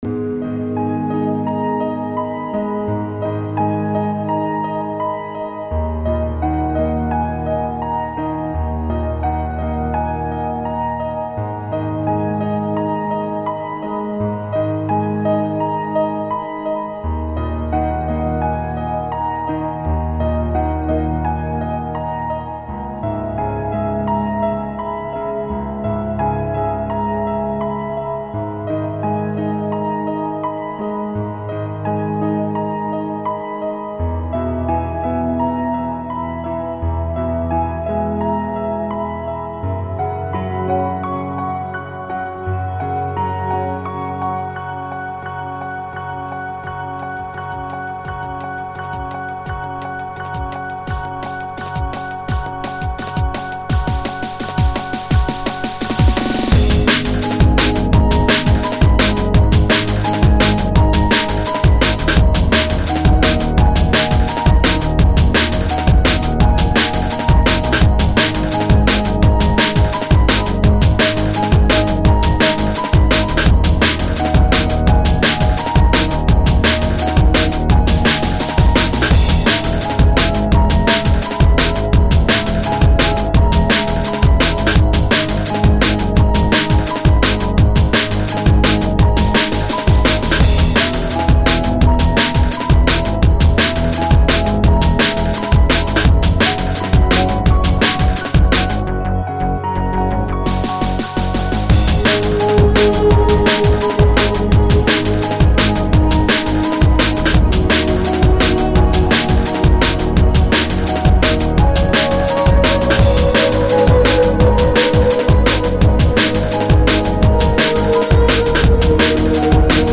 Dm_and_Bass_-_красиво
Dm_and_Bass___krasivo.mp3